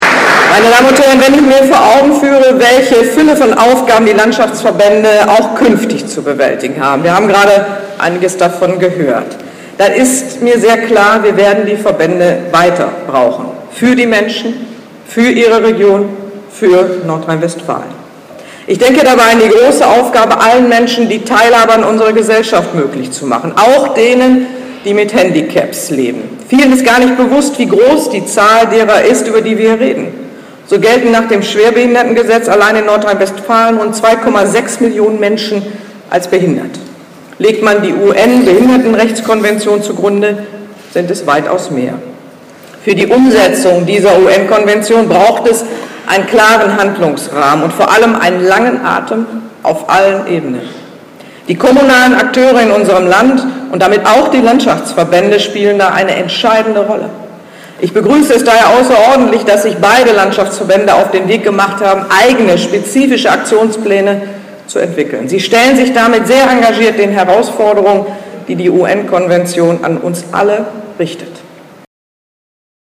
Durch Anklicken des Fotos gelangen Sie zur Ansprache von NRW-Minister-präsidentin Hannelore Kraft (Audiomitschnitt).
Kraft.mp3